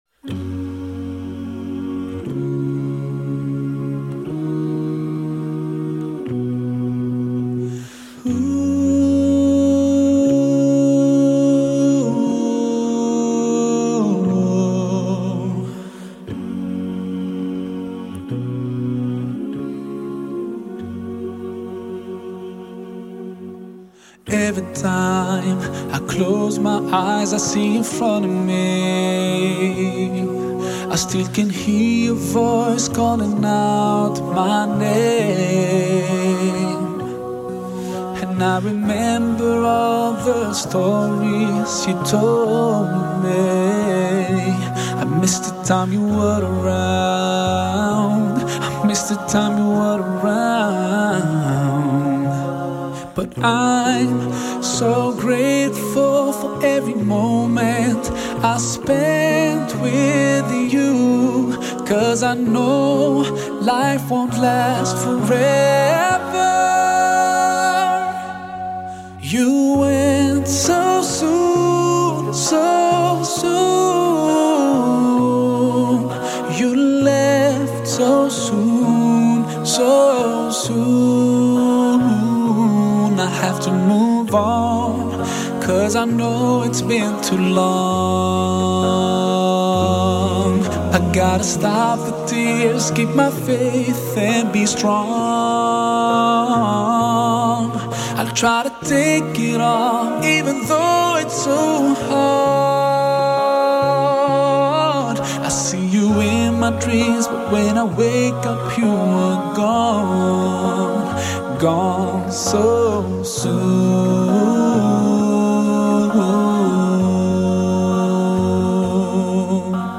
خواننده : موسیقی اسلامی
دسته : موسیقی ملل